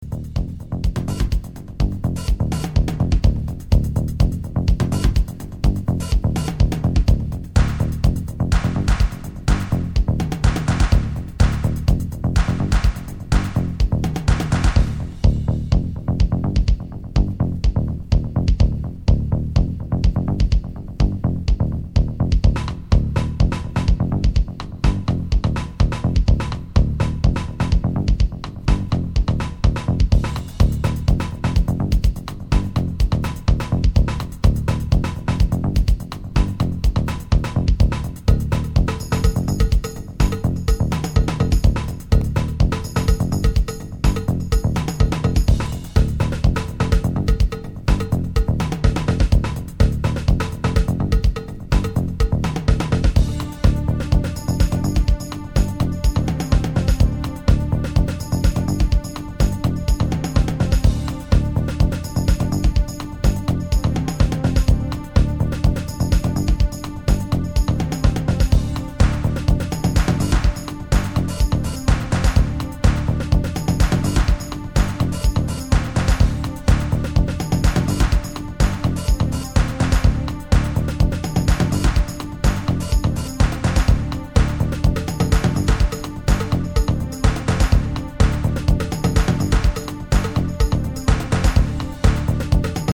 Analog house
House